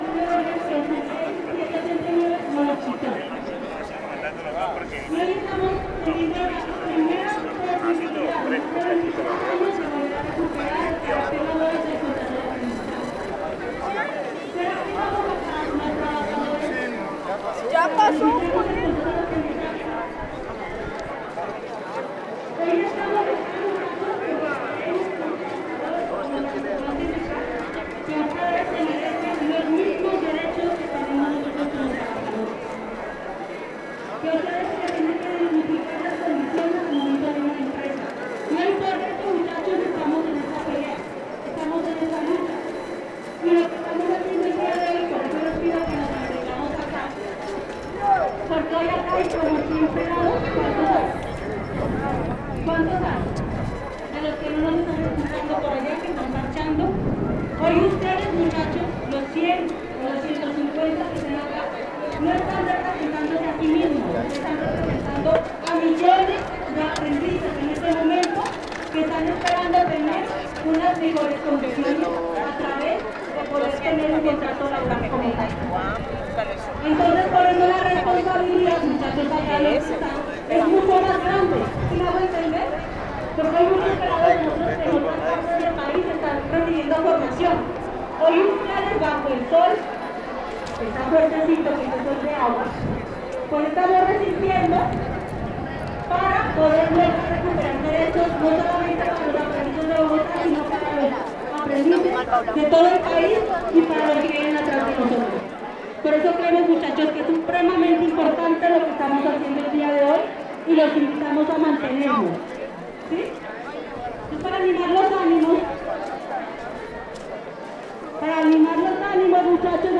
Plaza de Bolivar 13 hs. 14 de Junio 2025
oa-colombia-bogota-plaza-de-bolivar.mp3